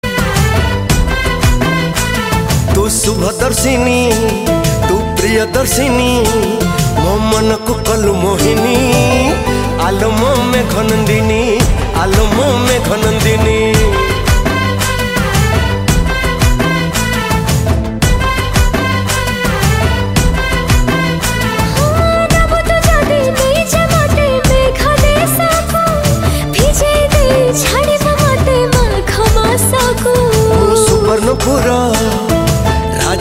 Odia Album Ringtones
dance songs